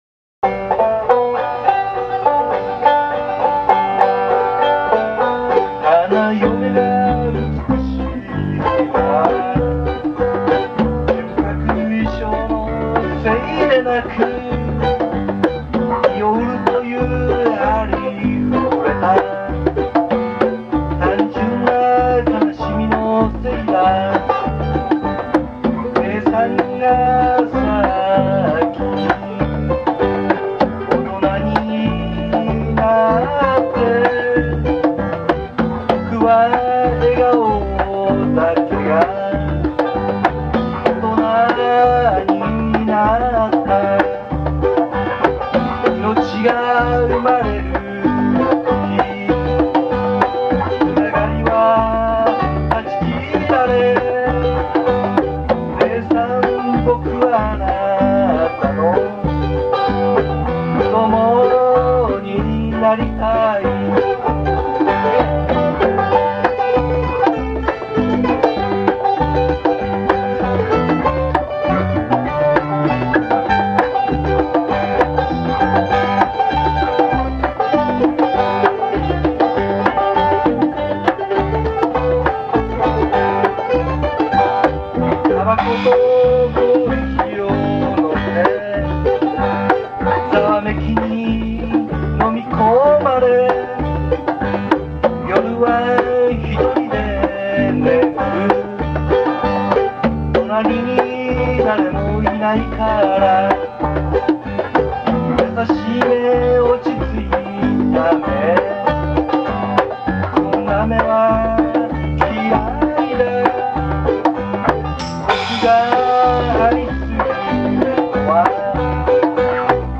ぼくは30数年前の「ジャンボ」のマンドリンで、この曲に
リハーサル・テイクのMP3ファイルの